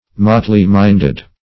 Motley-minded \Mot"ley-mind`ed\, a.